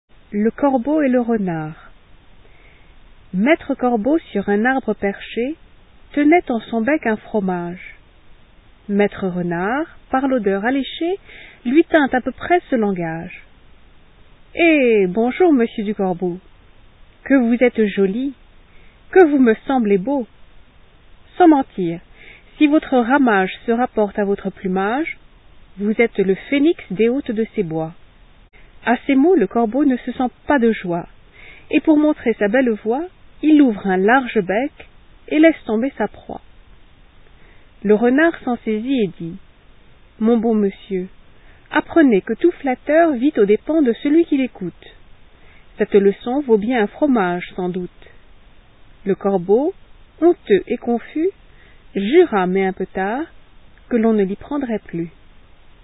フランス人による朗読音声
現代フランス人にとっては、ここに et が入るのが自然のようです。